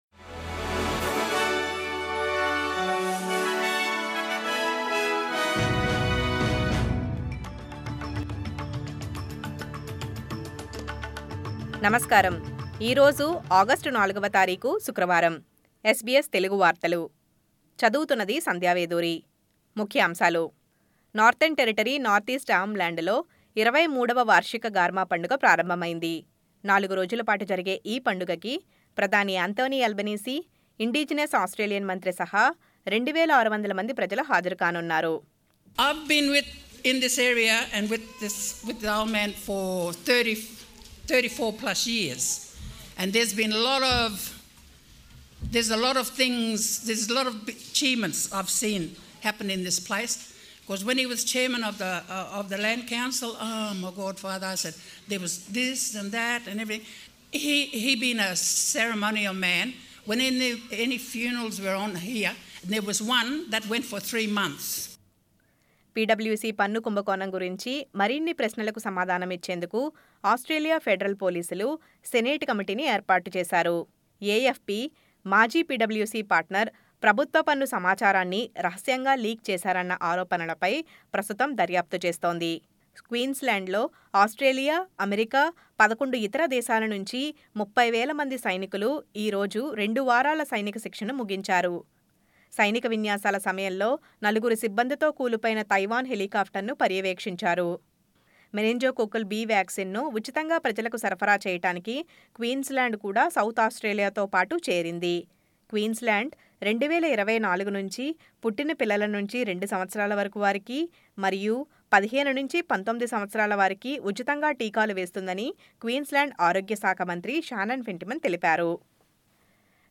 SBS తెలుగు 04/08/23 వార్తలు: Arnhem Land లో 23 వ వార్షిక గార్మా పండుగ ప్రారంభమైంది.